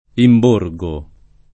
imborgare v.; imborgo [